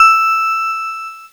Cheese Note 22-E4.wav